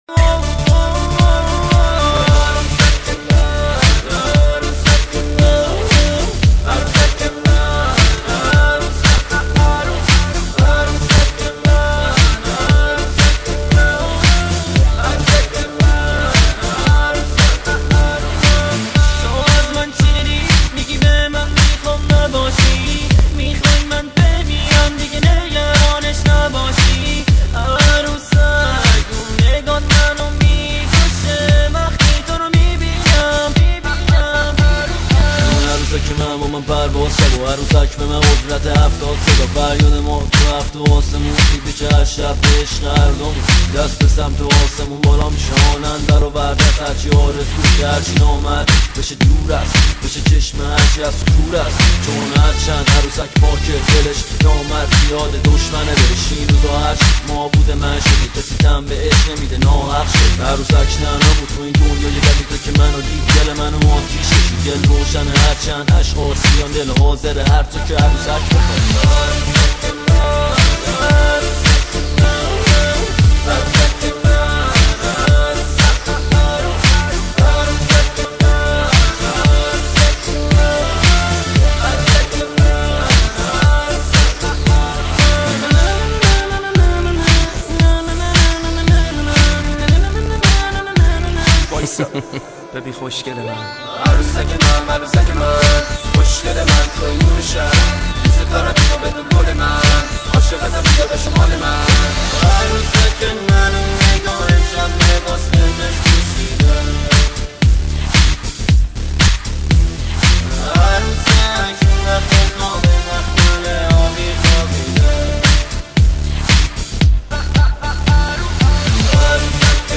سبک هیپ هاپ